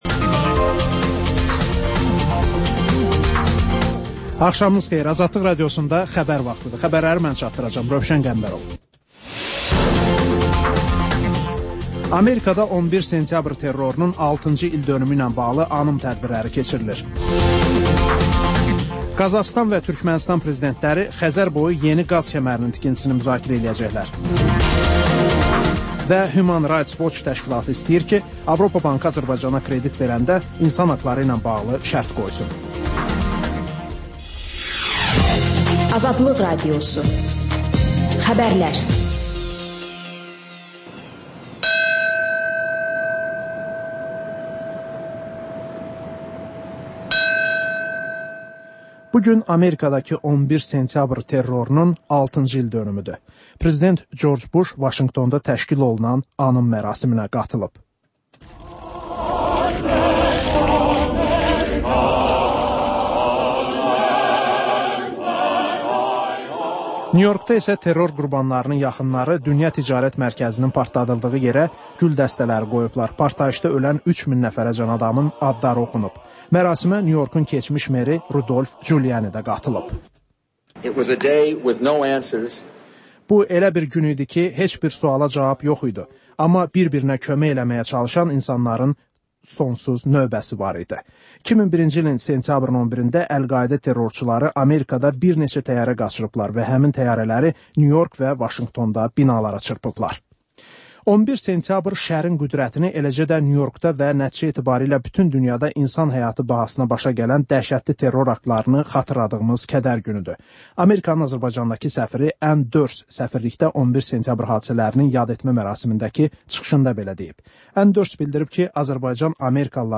Xəbərlər, müsahibələr, hadisələrin müzakirəsi, təhlillər